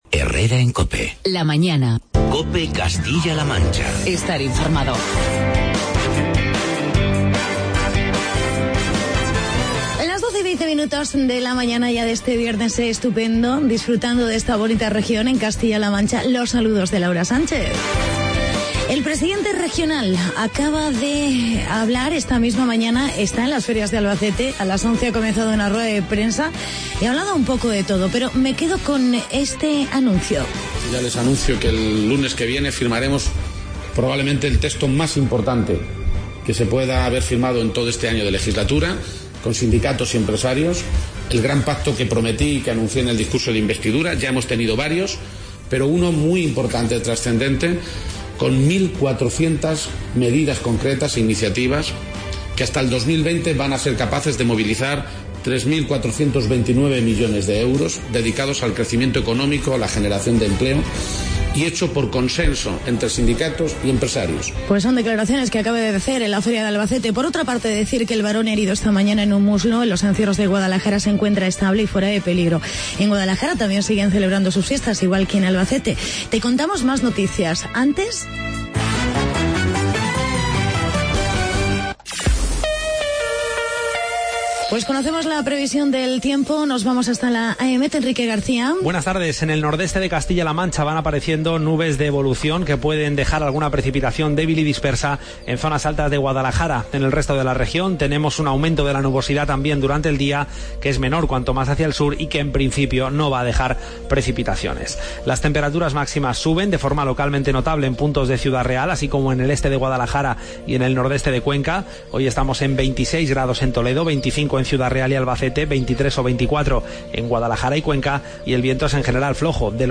Presenta: Actualidad y entrevista